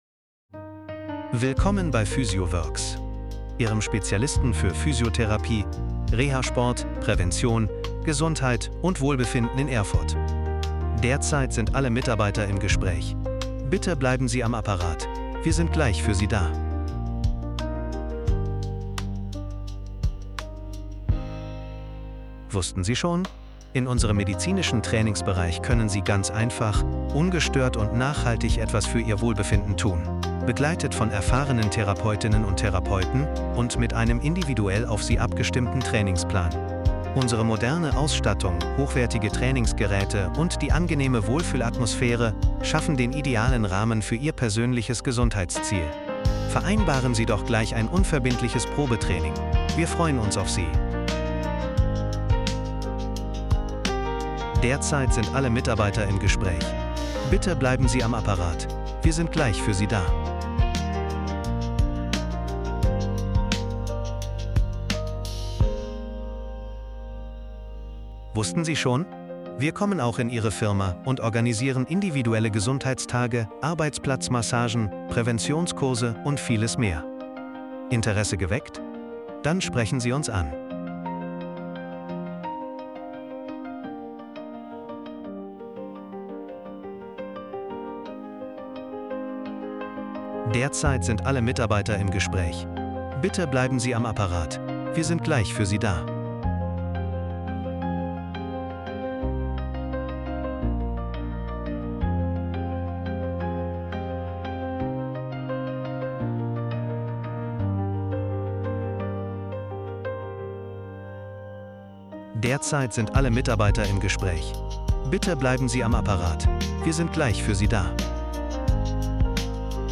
Telefonansagen & Warteschleifen Beispiel
So klingt eine professionelle Telefonansage & Warteschleife aus unserem Studio.
wartefeldansage-soundbeispiel-vision-decades.mp3